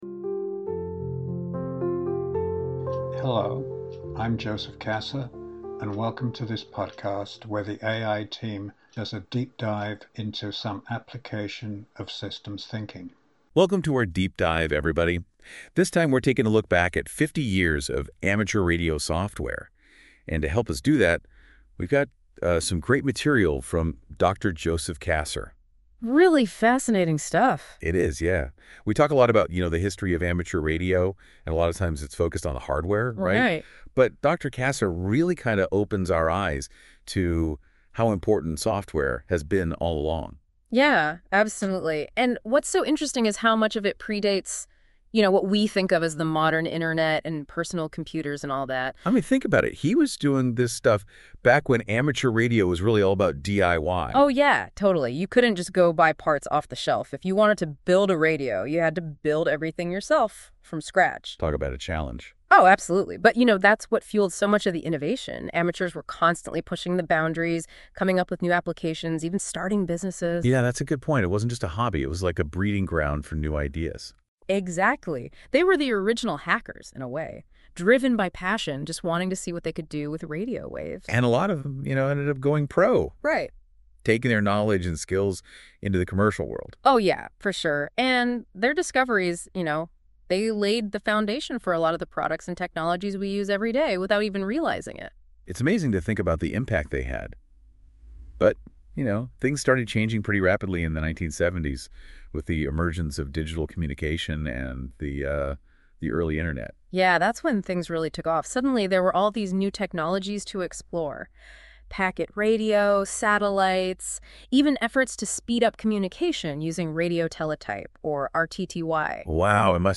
The AI team take a deep dive into successful innovative tools, practical and conceptual applications of systems thinking and beyond to various types of problems, summarizing the concepts behind the successes and usually drawing general conclusions for how the concepts may be used in other situations. The opinions expressed by the AI team in each deep dive are their own and have not been edited in any way.